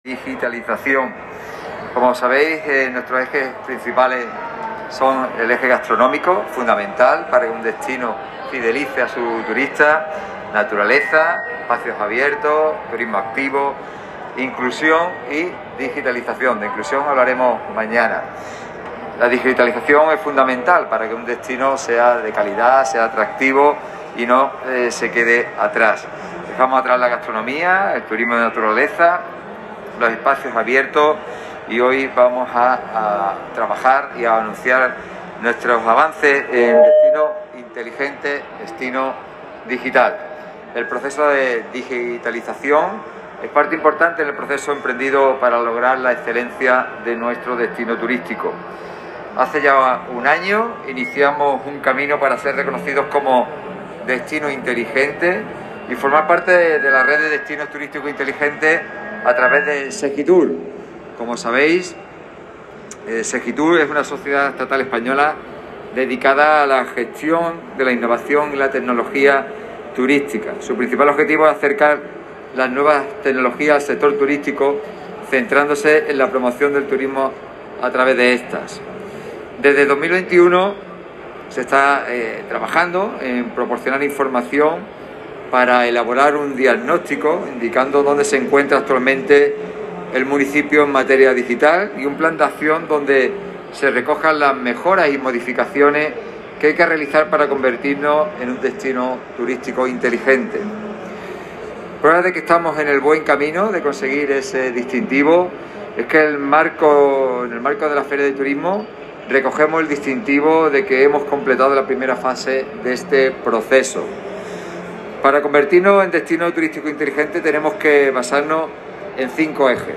alcalde-destino-inteligente.mp3